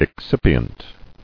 [ex·cip·i·ent]